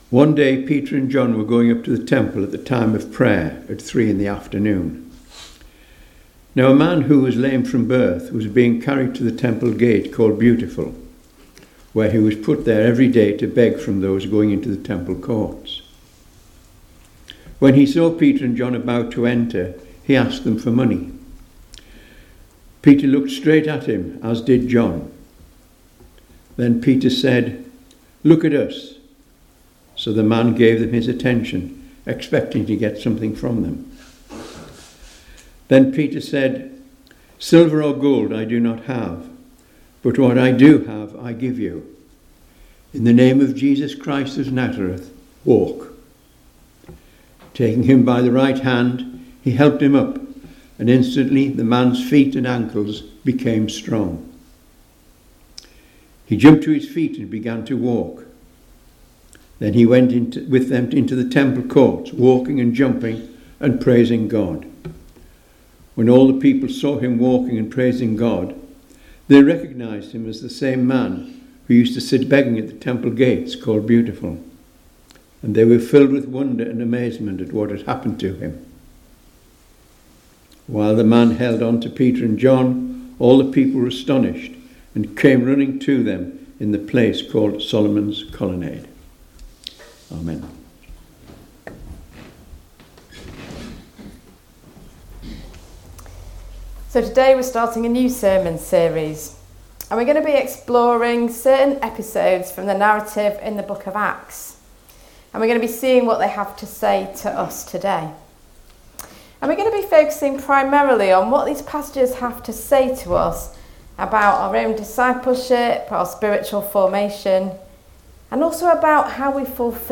You can listen to both the reading and Sermon by tapping the link below: